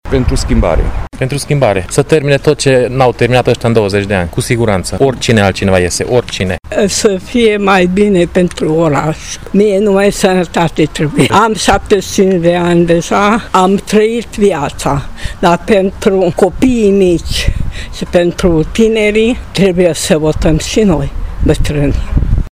Aceștia spun că își doresc schimbare și o viață mai bună pentru tineri: